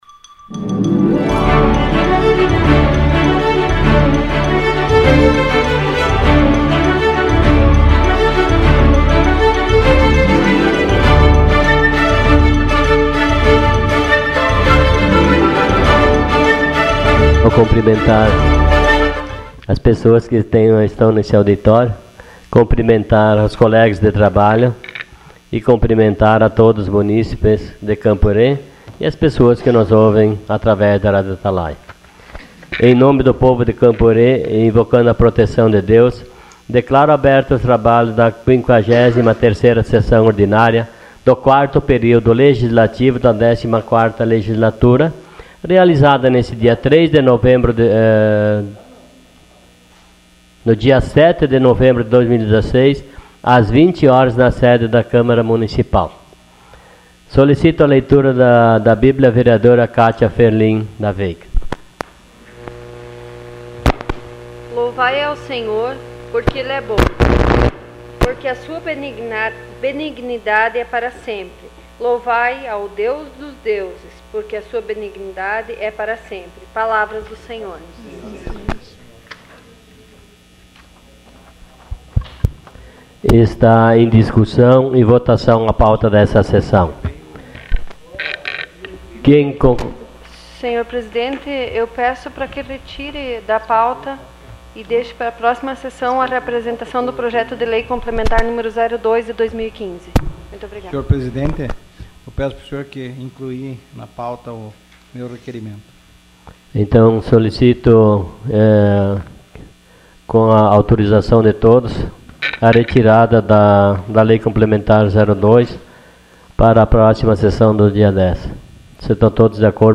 Sessão Ordinária dia 07 de novembro de 2016.